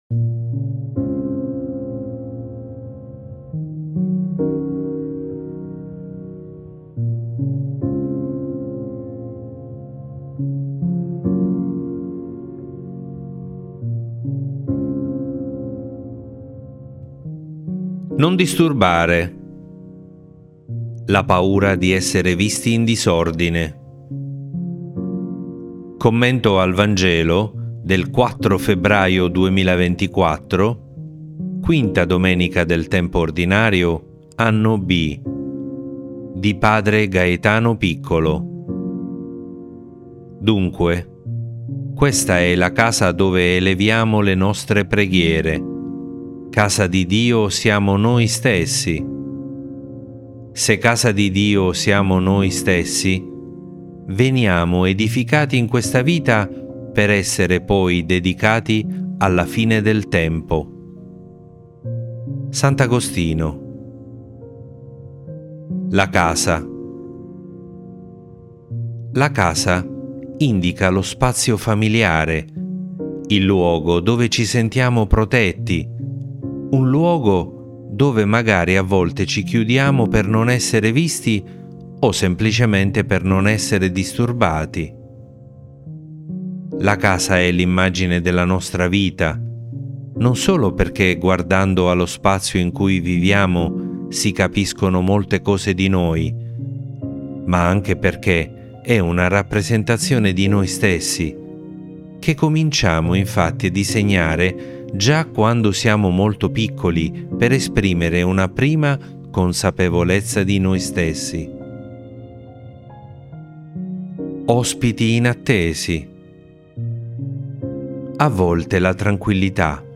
Non disturbare! La paura di essere visti in disordine. Commento al Vangelo del 4 febbraio 2024